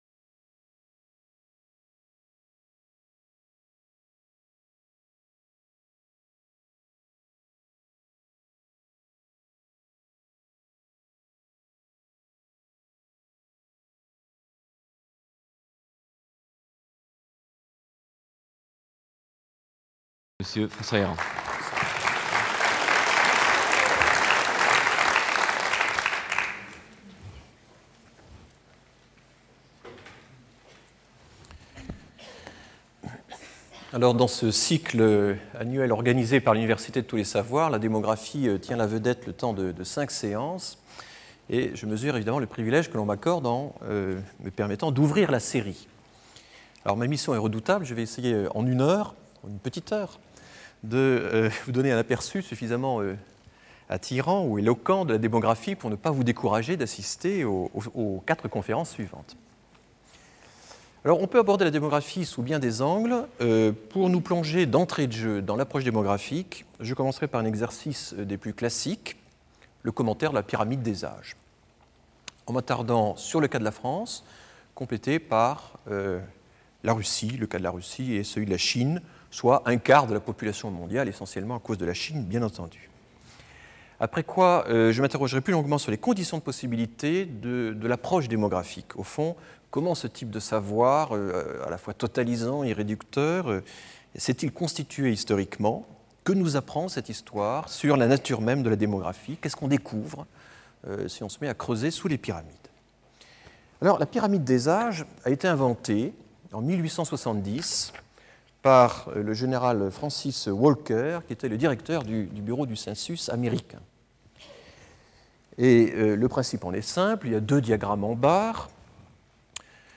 Conférence du 23 février 2000 par François Héran. La démographie traite des choses les plus élémentaires qui soit : la vie, l'amour, la mort, mais sous un angle très particulier.